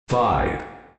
Announcer